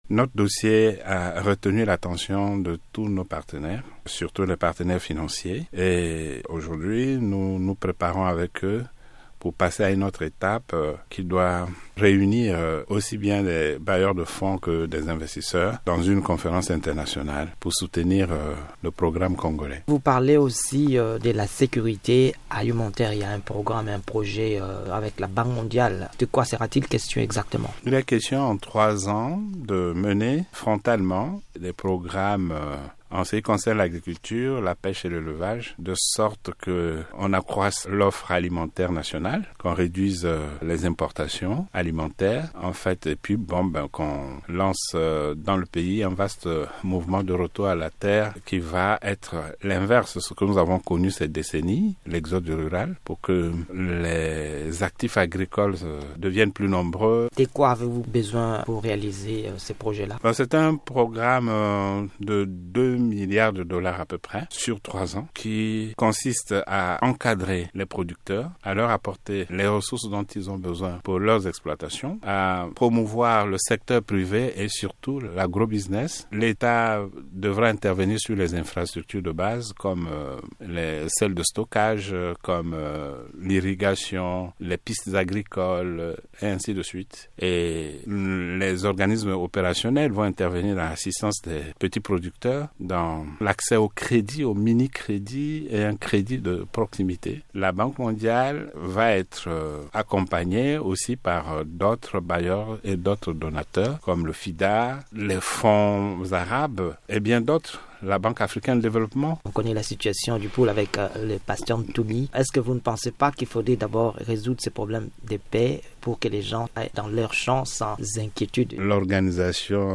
Le Congo lance un vaste programme de retour à la terre-Interview avec le ministre d’Etat Henri Djombo